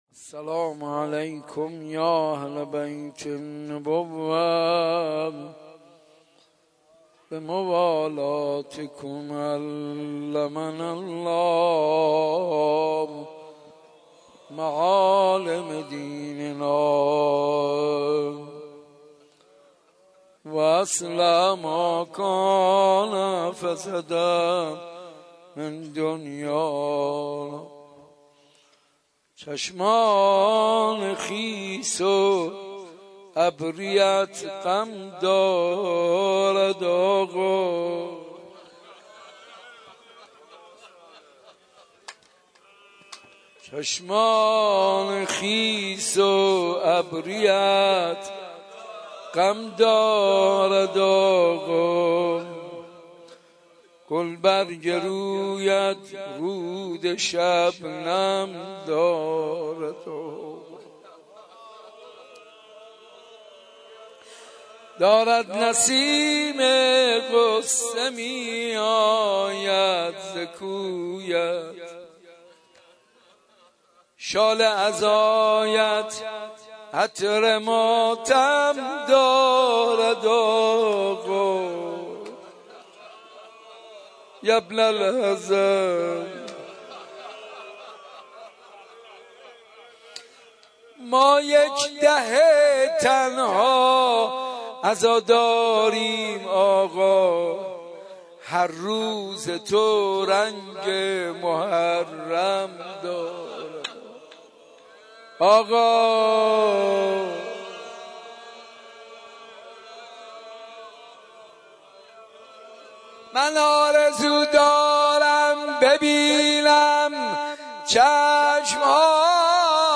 صوت مناجات و روضه